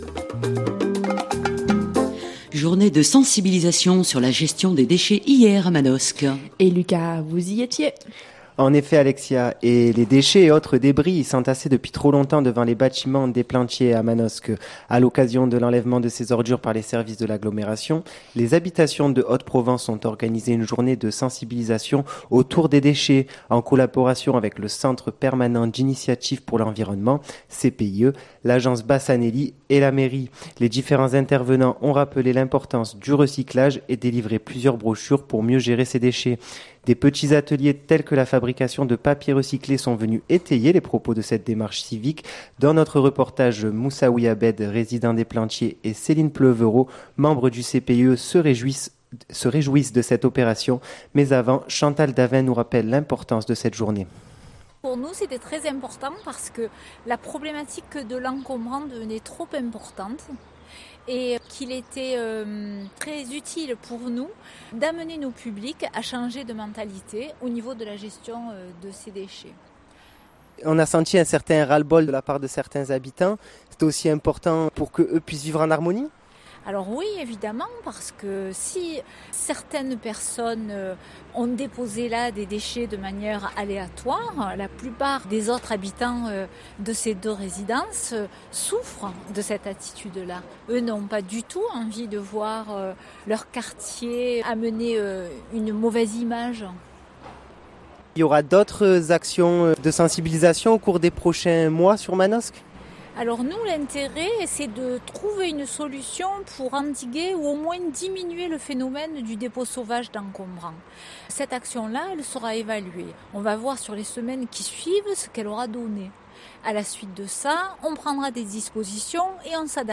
manosque___journee_de_sensibilisation.mp3 (4.61 Mo)